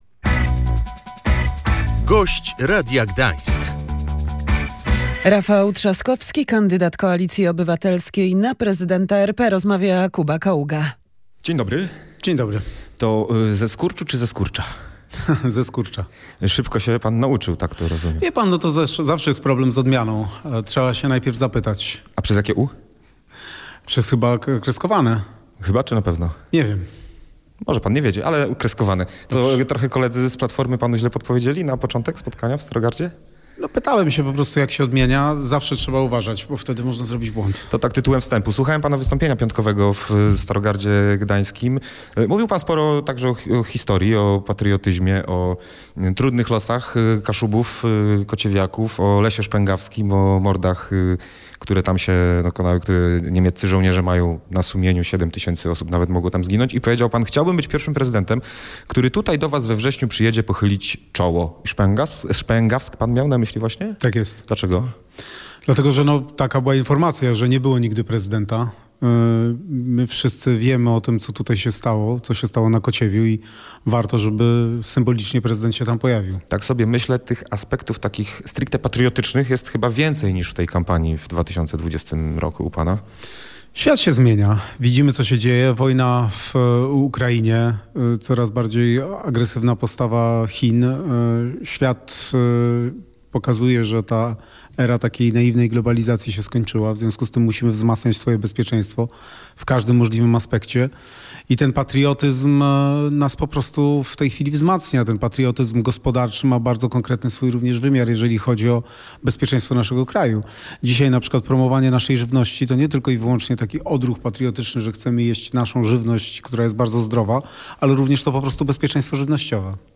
Czymś całkowicie naturalnym jest to, że po trzech latach wojny cała Europa – nie tylko Polska – zastanawia się co dalej – mówił w audycji „Gość Radia Gdańsk” kandydujący na prezydenta Polski prezydent Warszawy Rafał Trzaskowski, pytany w kontekście propozycji odebrania 800+ niepracującym uchodźcom z